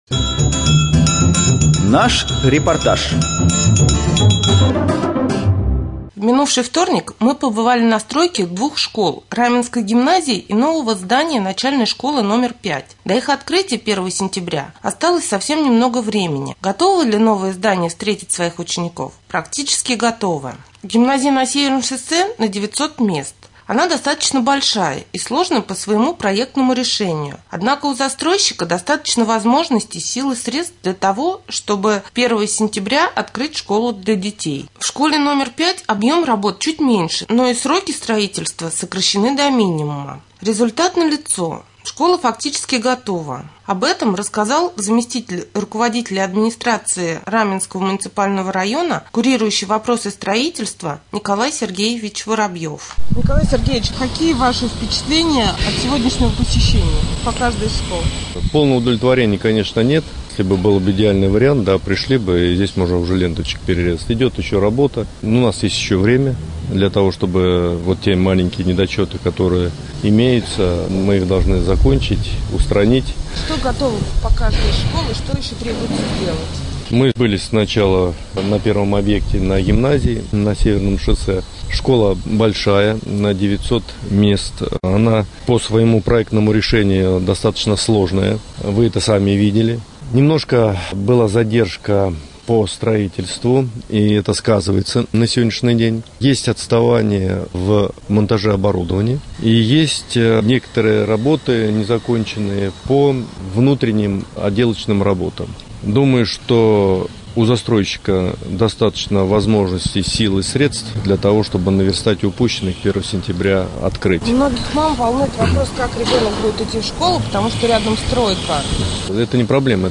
3.Рубрика «Специальный репортаж». Раменская гимназия и начальная школа №5 практически готовы.